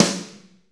Index of /90_sSampleCDs/Northstar - Drumscapes Roland/DRM_Slow Shuffle/SNR_S_S Snares x